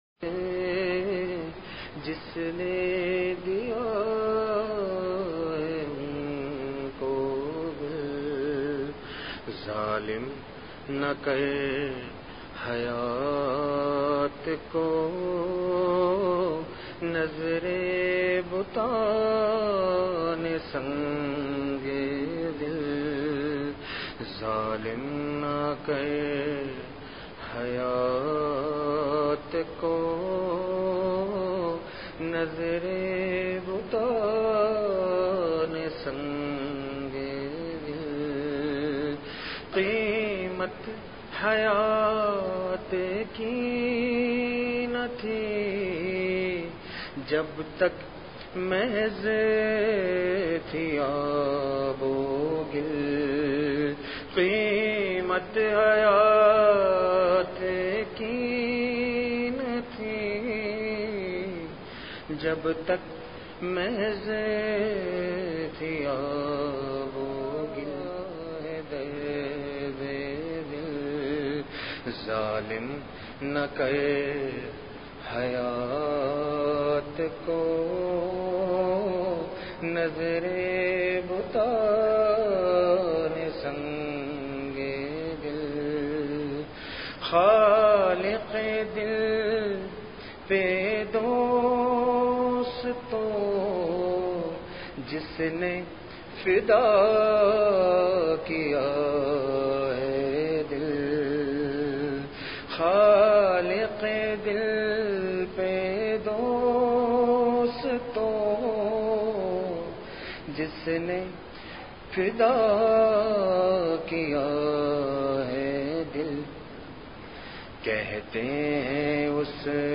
Please download the file: audio/mpeg مجلس محفوظ کیجئے اصلاحی مجلس کی جھلکیاں بمقام۔
بعد مغرب بیان مدرسہ سے متصل مسجد میں حضرت والا کا پرنور بیان ہوا۔
اماں عائشہ صدیقہ رضی اللہ تعالی عنہ پر تہمت لگنے کا واقعہ بہت زیادہ روتے ہوئے گریہ و زاری کے ساتھ۔